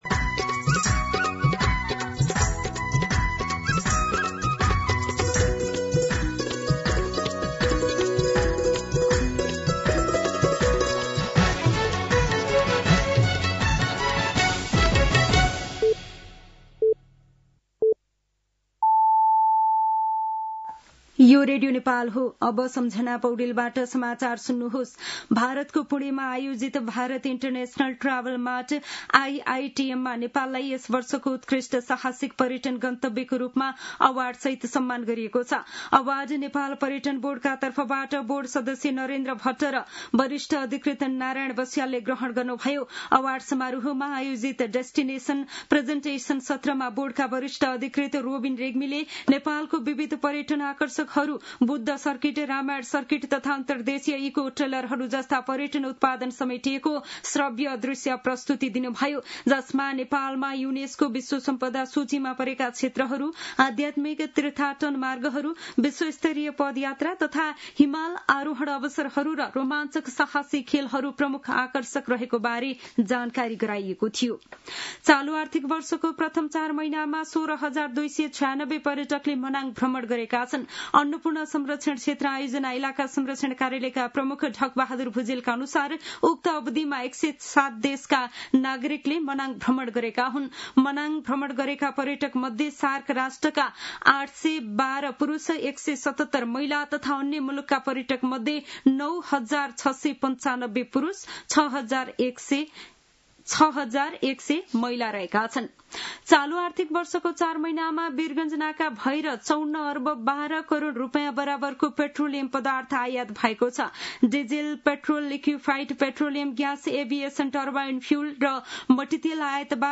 दिउँसो ४ बजेको नेपाली समाचार : १३ मंसिर , २०८२
4-pm-Nepali-News-4.mp3